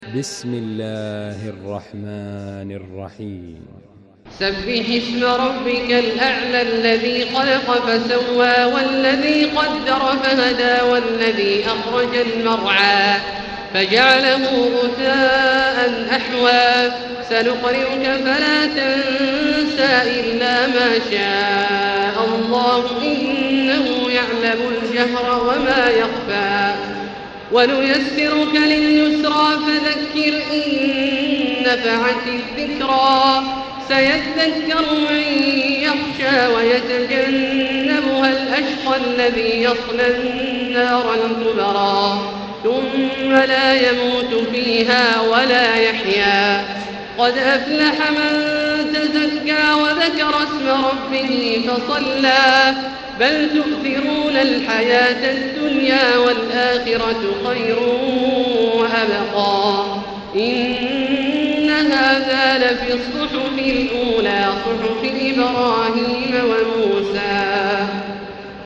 المكان: المسجد الحرام الشيخ: فضيلة الشيخ عبدالله الجهني فضيلة الشيخ عبدالله الجهني الأعلى